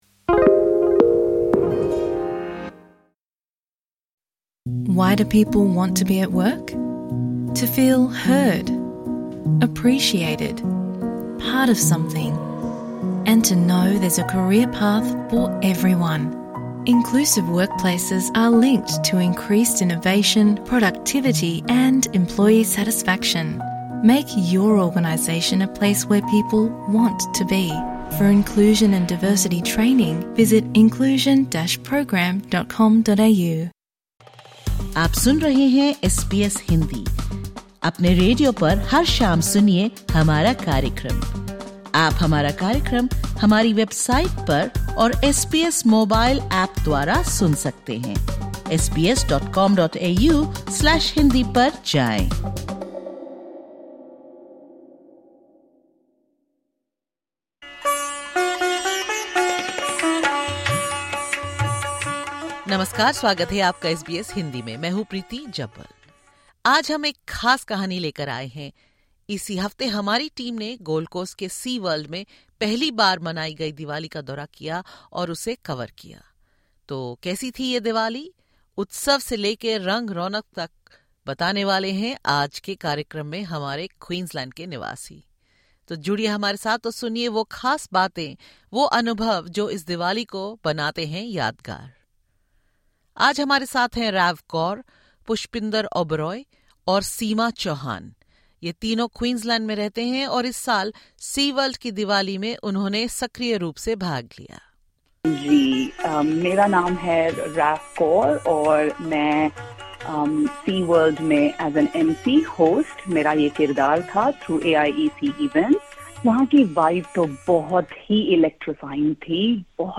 'It's about belonging': Community voices at first-ever Diwali celebration at Sea World
At the Sea World Diwali event, community leaders from across Queensland shared their reflections on the significance of celebrating Diwali in Australia, how it compares to traditions in India, and what the festival means in the context of current national debate on migration.